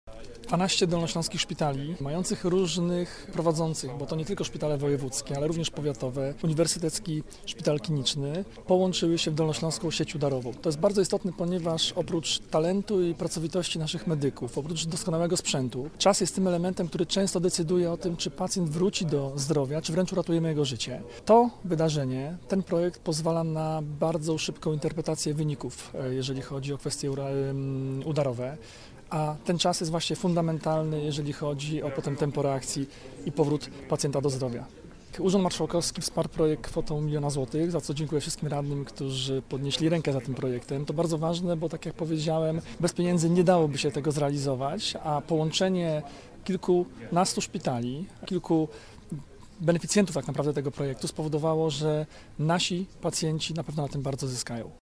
O projekcie opowiedział członek zarządu województwa Jarosław Rabczenko.